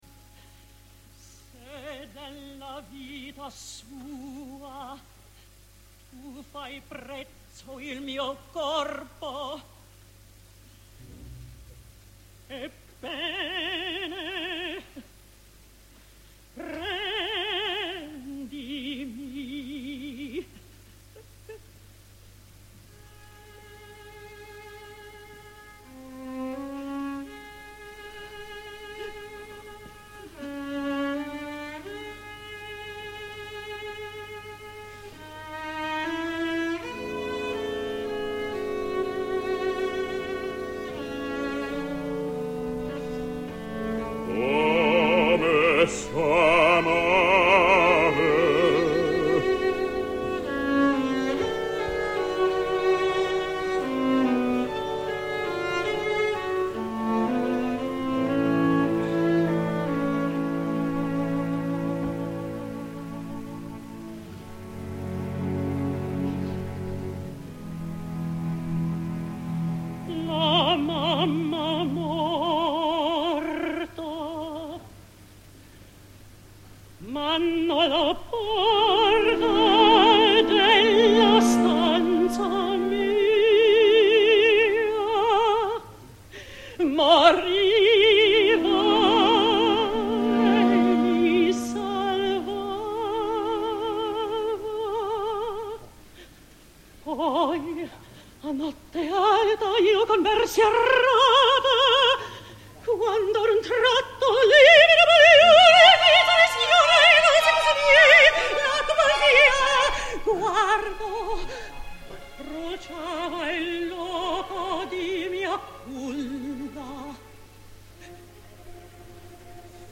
La representació d’avui es retransmet per Radio Clásica de RN a les 20:00.
Així ha cantat Deborah Voigt La Mamma Morta (29.09.07):